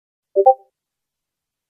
Discord Notification